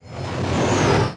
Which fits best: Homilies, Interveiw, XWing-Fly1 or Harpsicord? XWing-Fly1